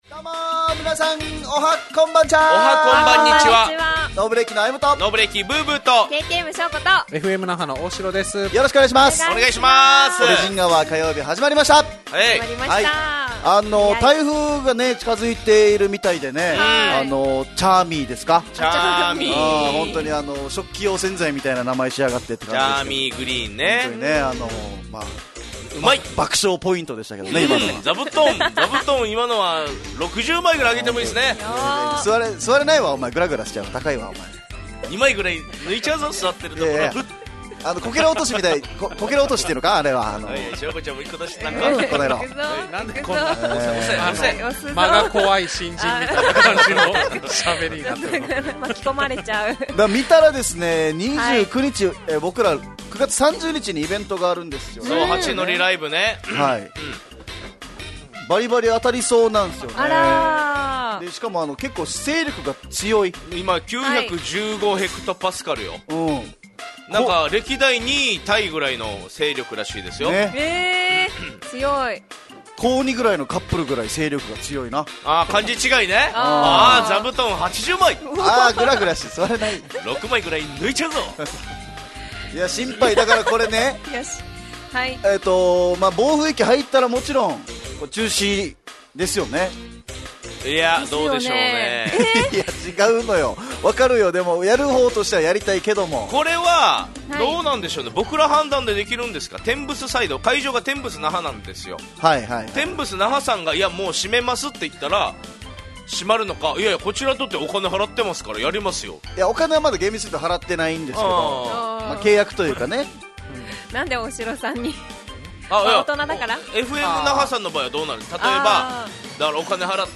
fm那覇がお届けする沖縄のお笑い集団・オリジンメンバー出演のバラエティ番組